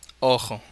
Voiceless uvular fricative - Wikipedia
Spanish European[3][4] ojo
[ˈo̞ʀ̝̊o̞] 'eye' Fricative trill; frication is velar in Madrid. Occurs in northern and central varieties.[3][4] Most often, it is transcribed with ⟨x⟩ in IPA.
Ojo_spanish_J.wav.mp3